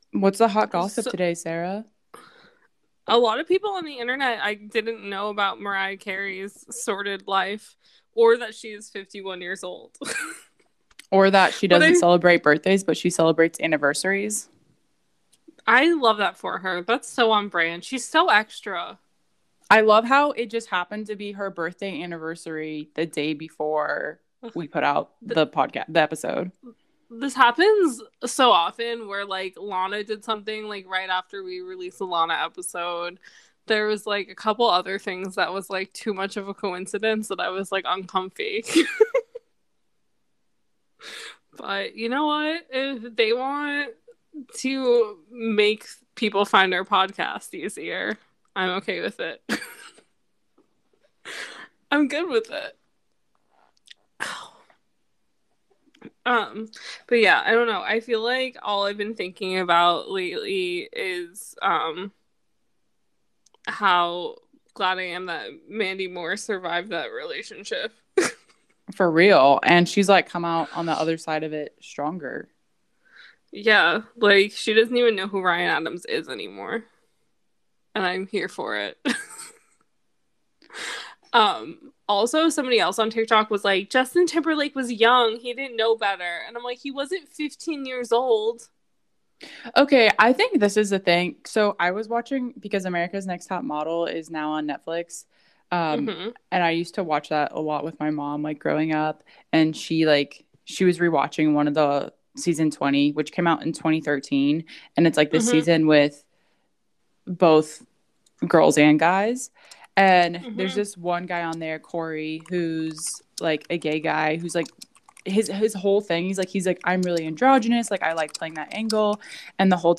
Some of our incredible listeners joined us to share their thoughts and feelings on the episode, and we expanded on the conclusions we came to during the episode.